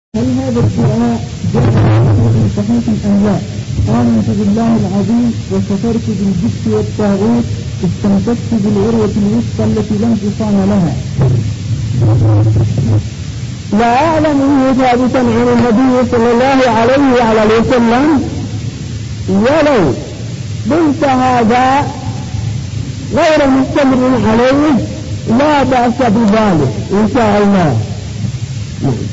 -------------- من شريط : ( أسئلة وأجوبة في صنعاء )